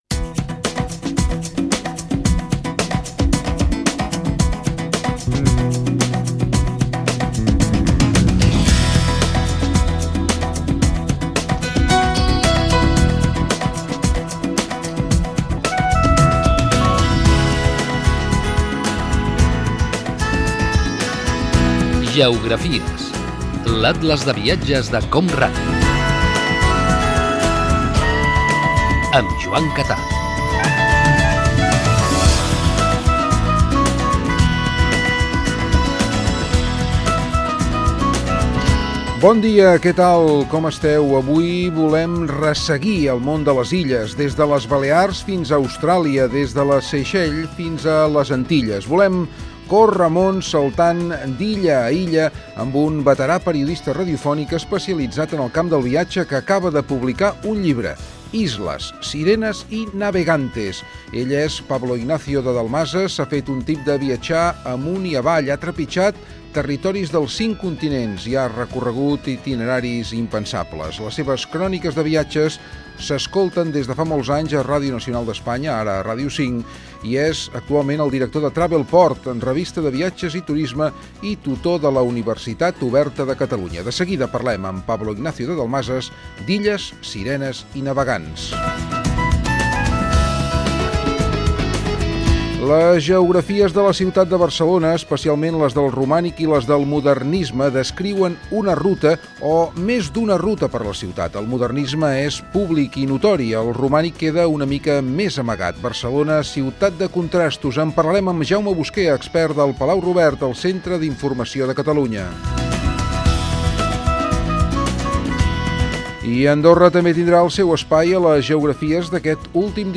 Careta del programa i sumari de l'atles de viatges de COM Ràdio.
Divulgació
FM